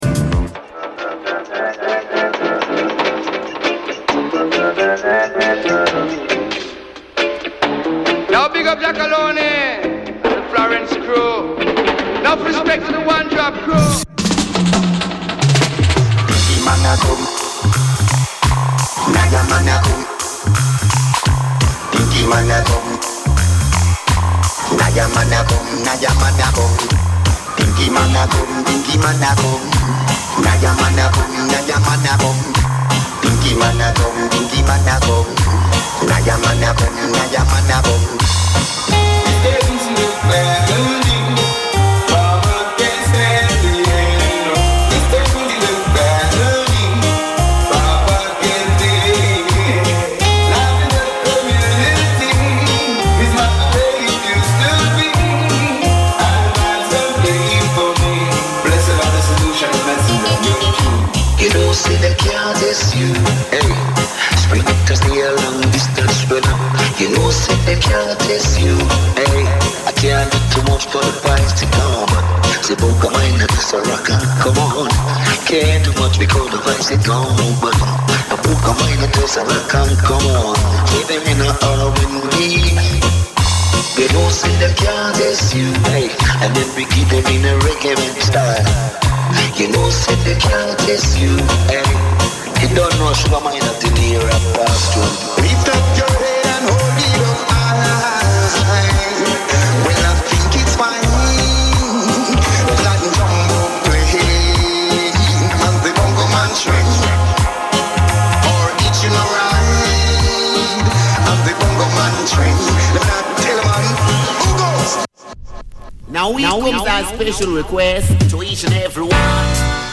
Roots Music, the best in reggae. Reggae Radio Show on air since 1991